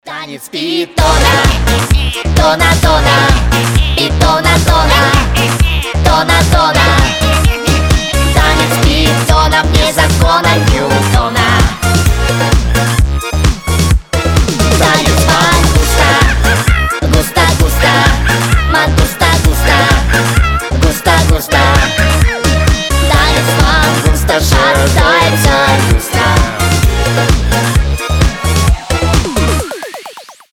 позитивные
веселые
динамичные
смешные
детские